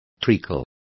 Complete with pronunciation of the translation of treacle.